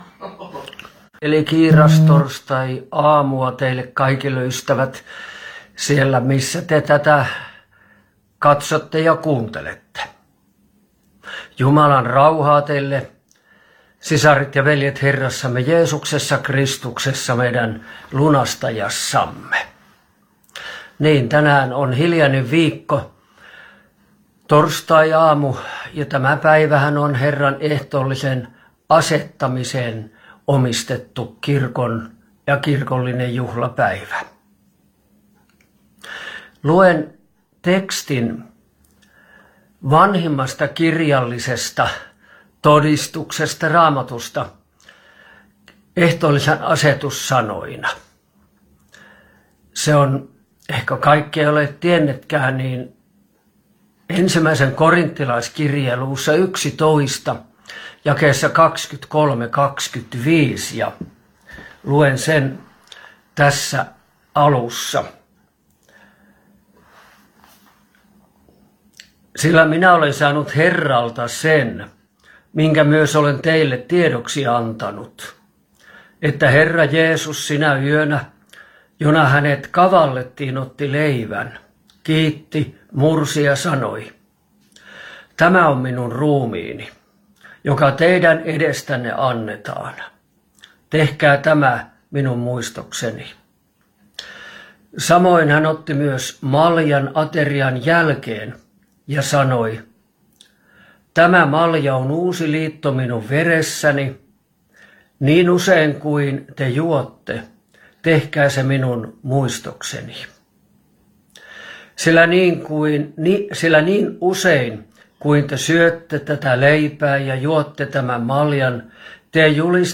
Lohtajalla kiirastorstaina